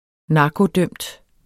Udtale [ -ˌdœmd ]